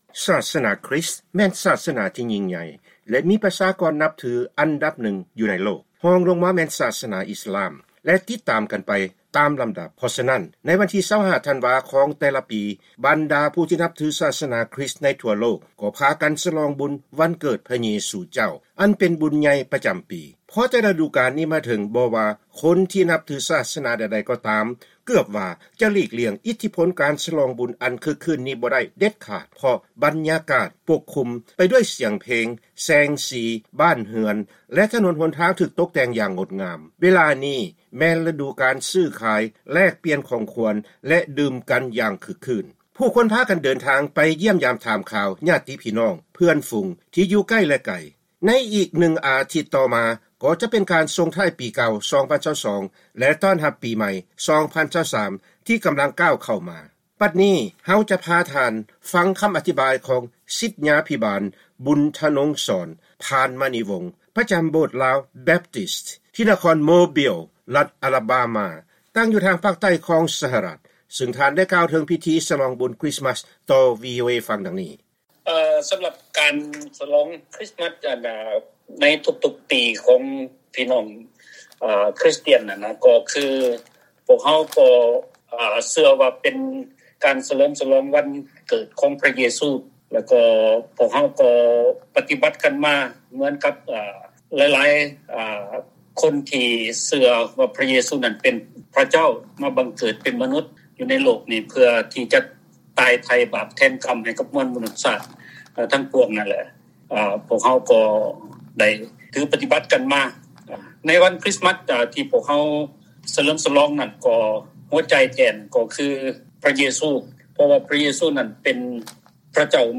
ເຮົາຈະນຳເອົາການໂອ້ລົມກັບຊາວລາວຢູ່ໃນສະຫະລັດອາເມຣິກາ ນິວຊີແລນ ແລະປະເທດຝຣັ່ງ